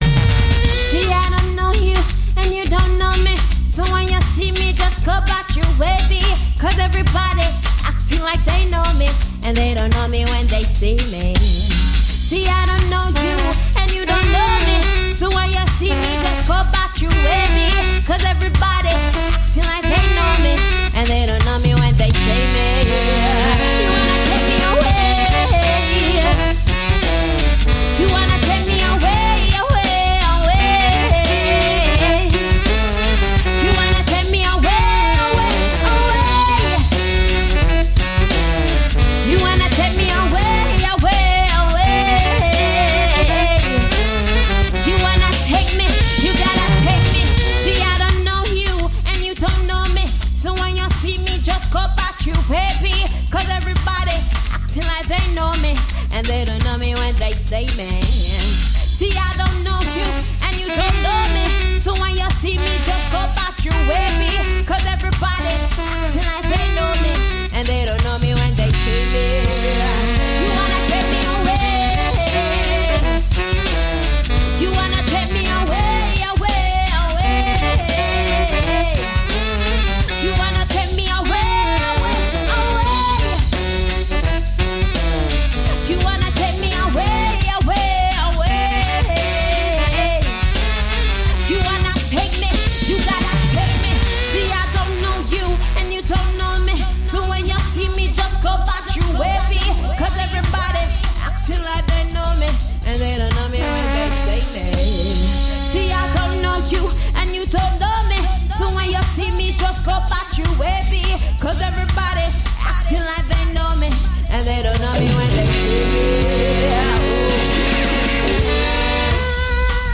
Interview with the principals behind Helsinki Hudson.